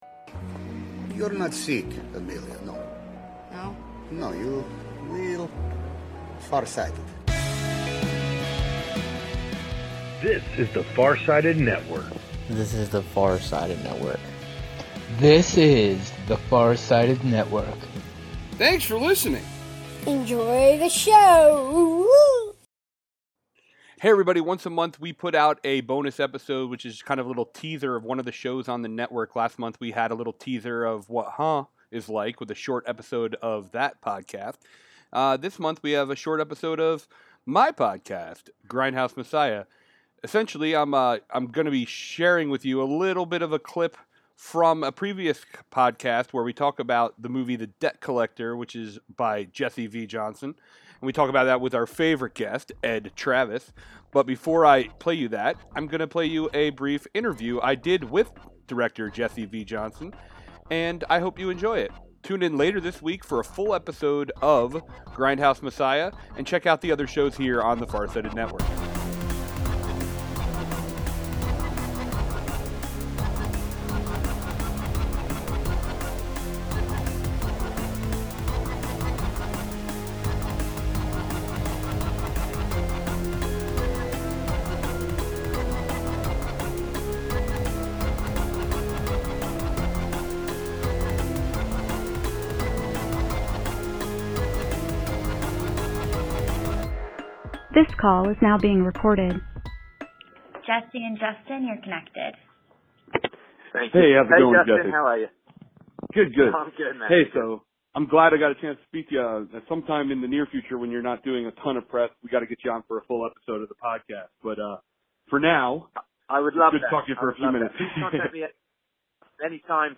BONUS: AVENGEMENT’s Jesse V. Johnson Stops By For a Quick Chat